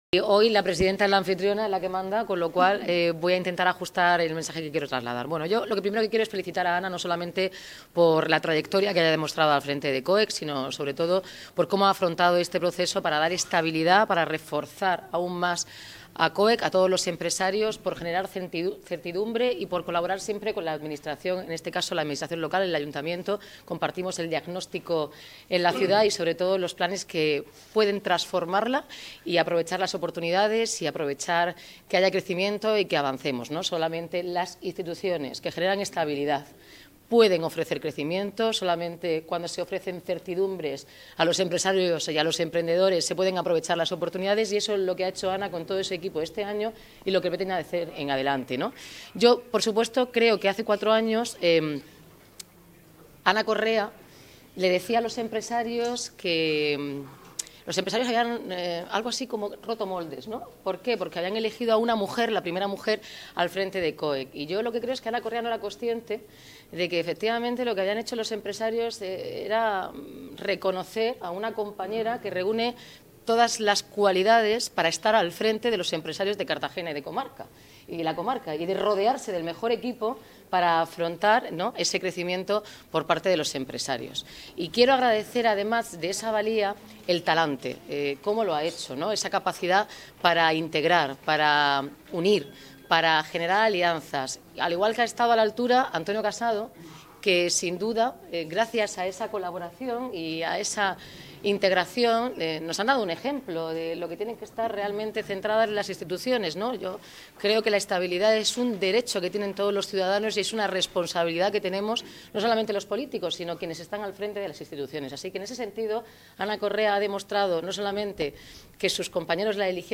Enlace a Asamblea General de COEC.